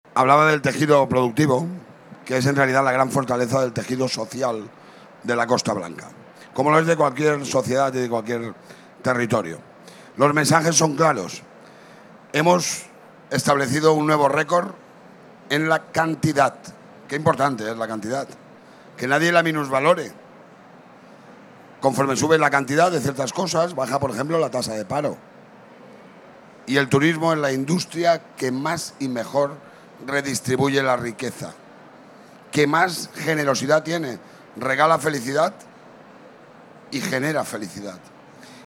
El acto, celebrado en la Plaza Central del estand de la Comunitat Valenciana, ubicado en el pabellón 7 de IFEMA, ha contado con la presencia del president de la Generalitat, Carlos Mazón, encargado de clausurar la presentación, así como de las vicepresidentas de la Diputación, Ana Serna y Marina Sáez, y diputados provinciales.